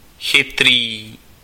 Ääntäminen
France: IPA: [ɛ̃.te.li.ʒɑ̃]